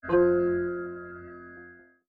SFX_Menu_Confirmation_04.wav